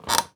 chair_frame_metal_creak_squeak_10.wav